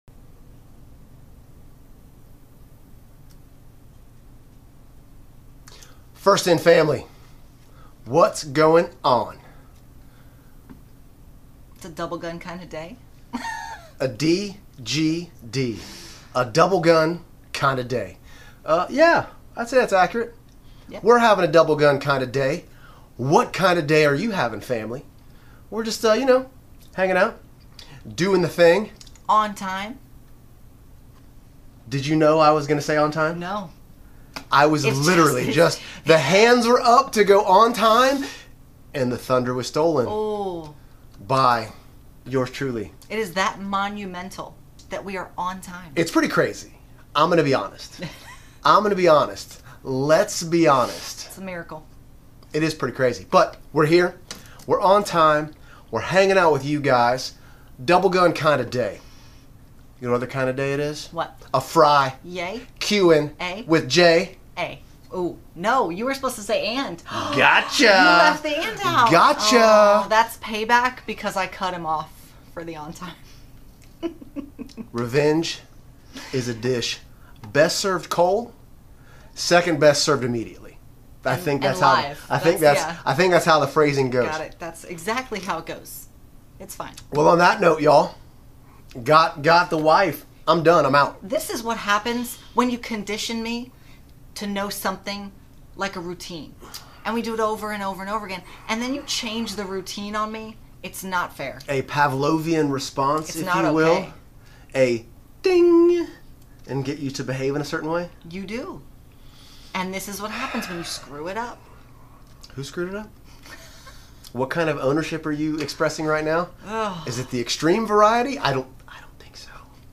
FIN LIVE Q+A: 9/8/23